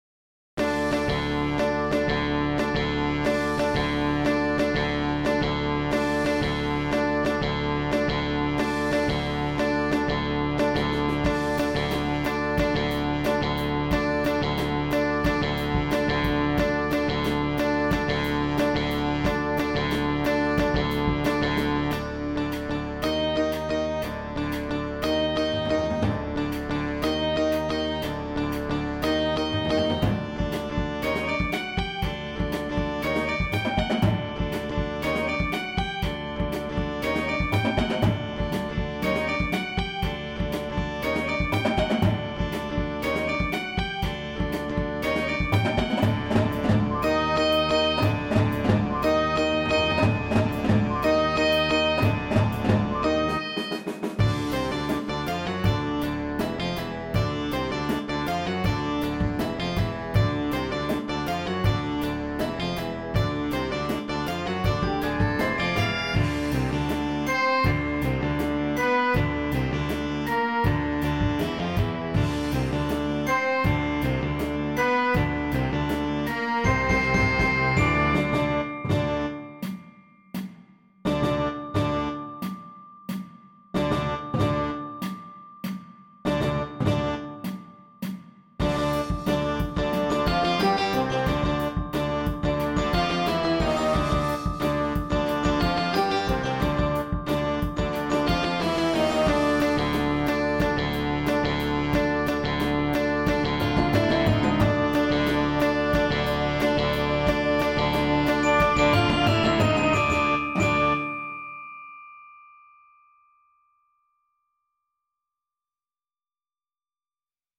is very exciting for young concert band players.